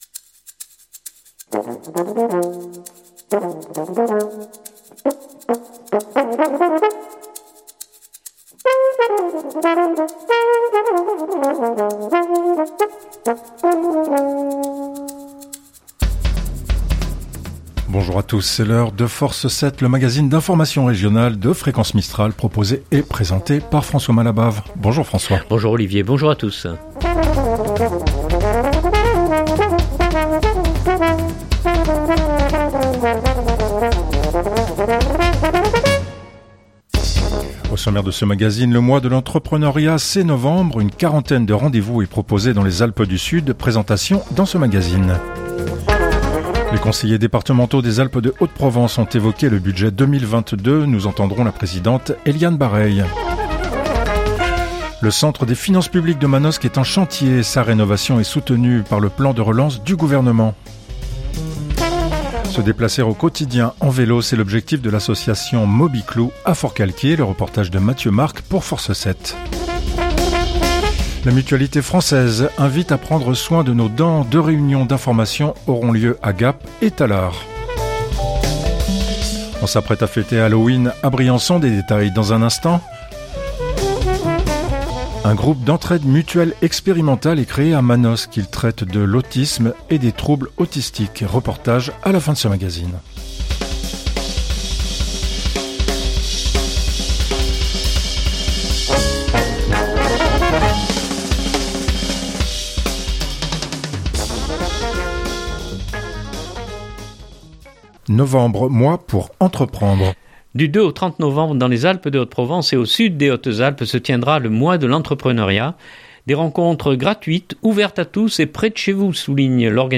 - Les conseillers départementaux des Alpes de Haute-Provence ont évoqué le budget 2022. Nous entendrons la présidente Eliane Barreille.